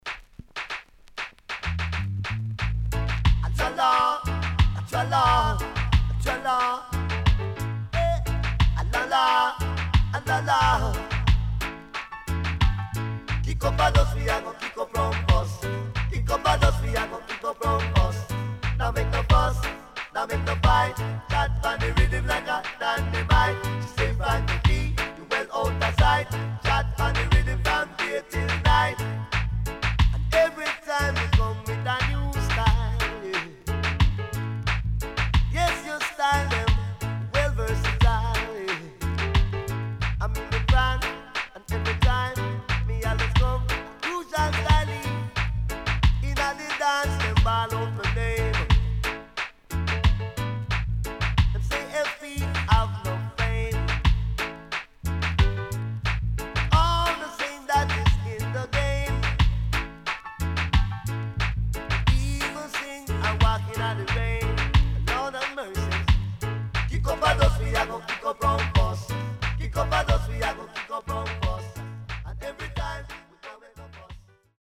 HOME > LP [DANCEHALL]  >  EARLY 80’s
SIDE B:少しノイズ入りますが良好です。